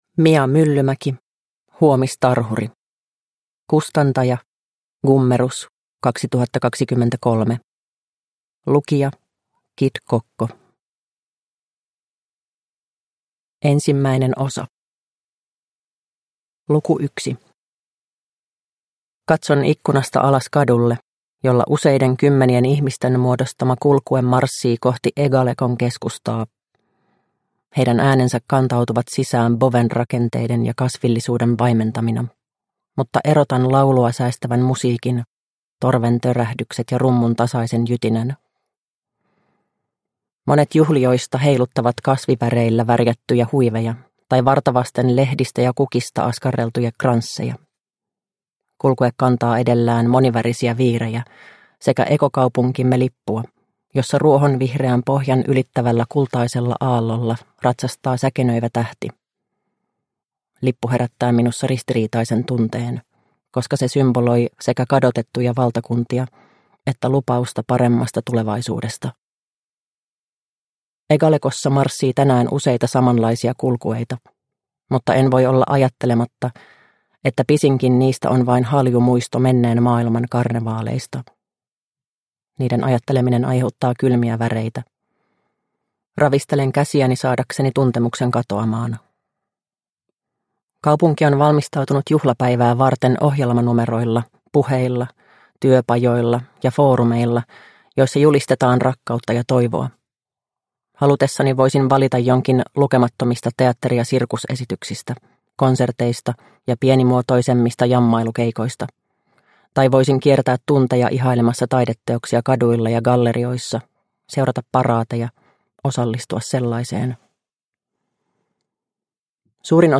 Huomistarhuri – Ljudbok – Laddas ner